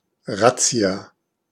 Ääntäminen
IPA: [ˈʀat͡si̯a]